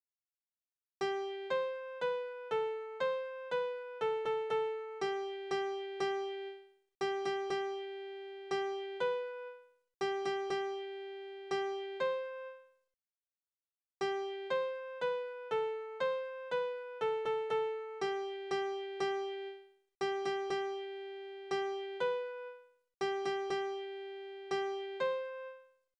Tonart: C-Dur
Taktart: 3/4
Tonumfang: Quarte
Besetzung: vokal
Anmerkung: Vortragsbezeichnung: Walzer